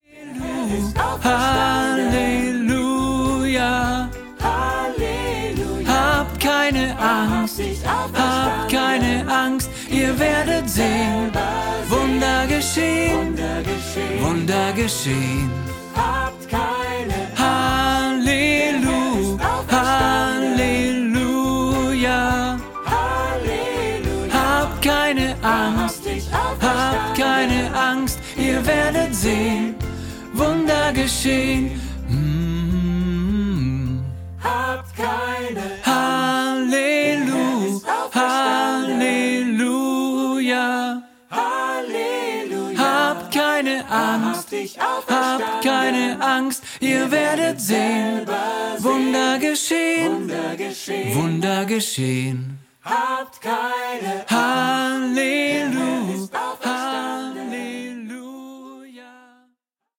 Lern-Tenor2